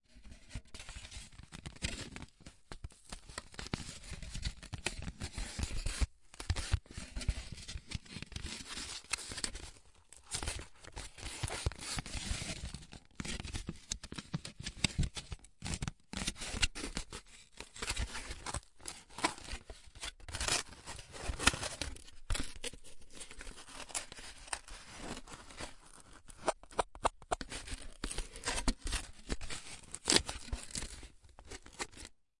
描述：两块聚苯乙烯手机摩擦的麦克风样本短促的刮擦声
标签： 冲击 聚苯乙烯 样品
声道立体声